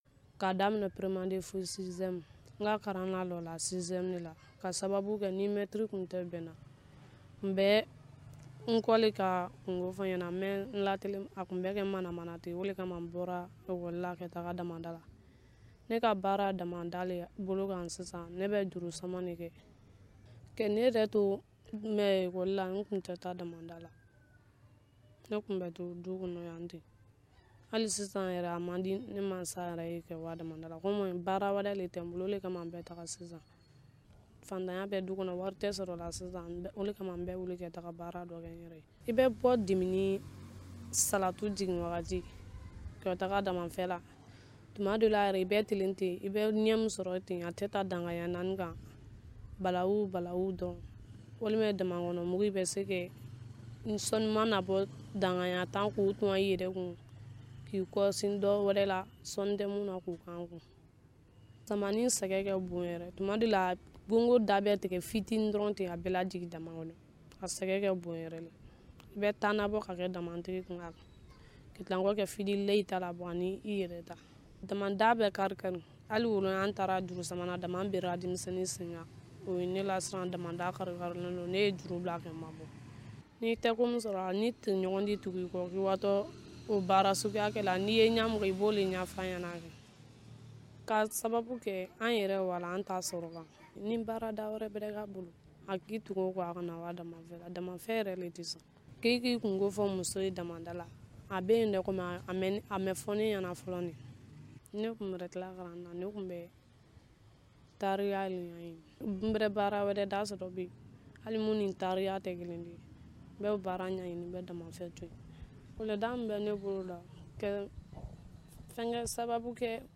Magazine en français: Télécharger